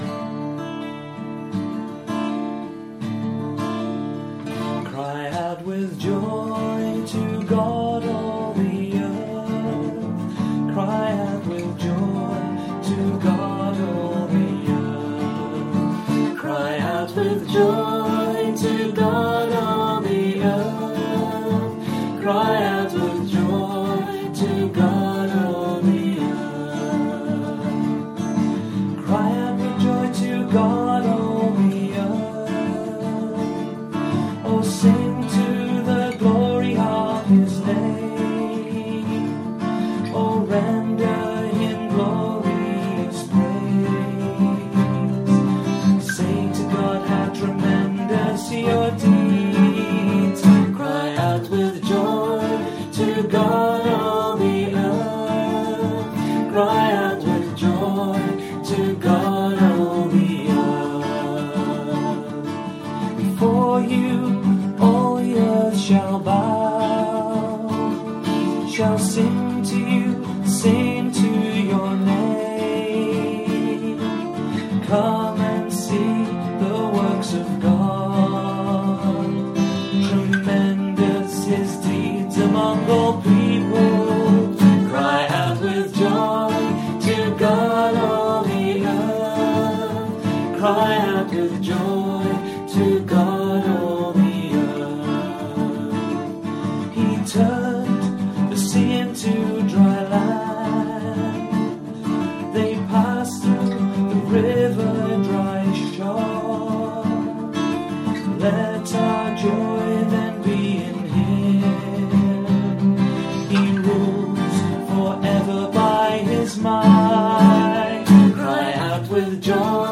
Responsorial Psalm for the 6th Sunday of Easter